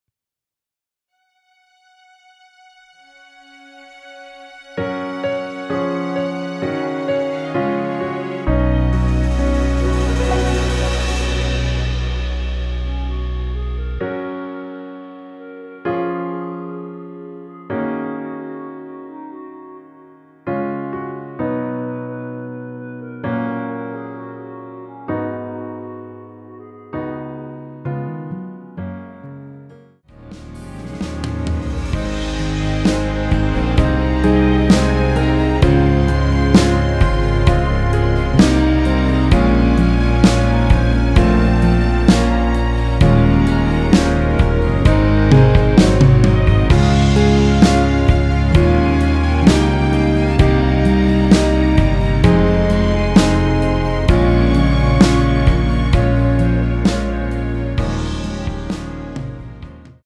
원키에서(-3)내린 멜로디 포함된 MR입니다.(미리듣기 확인)
F#
앞부분30초, 뒷부분30초씩 편집해서 올려 드리고 있습니다.
중간에 음이 끈어지고 다시 나오는 이유는